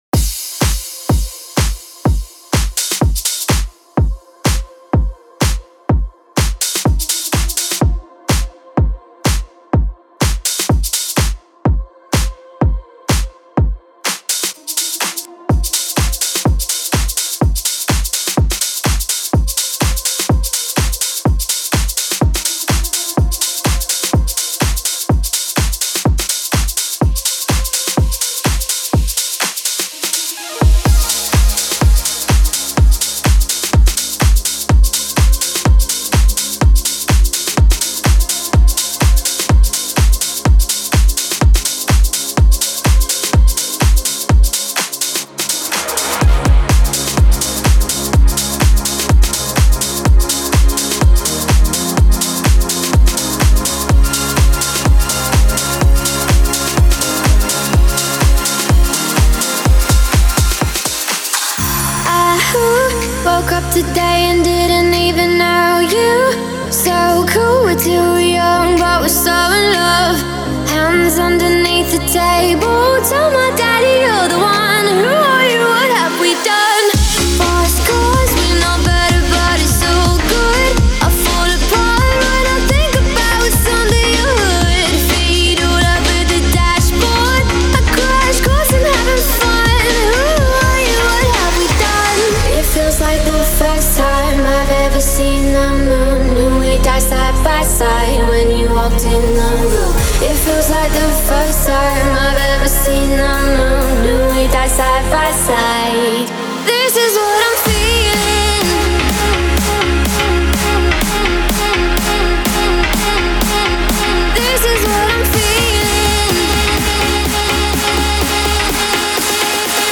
Стиль: Club House / Vocal House / Future House